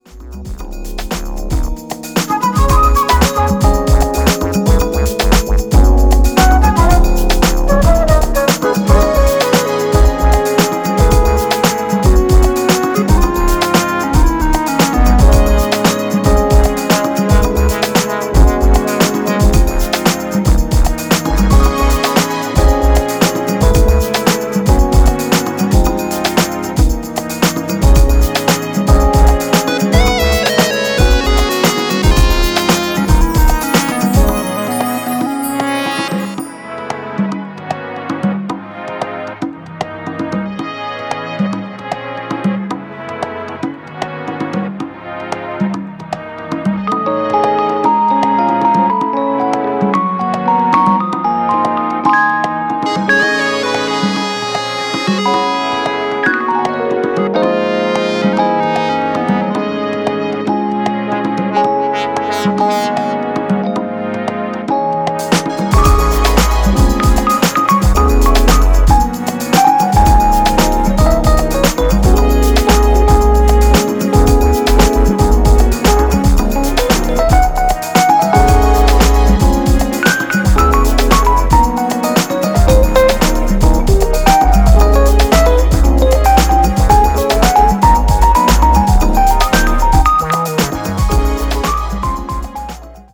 ここでは、自身の手による鍵盤やフルートなどのジャジーな演奏を用いながら温かくしなやかなフュージョン・ハウスを展開。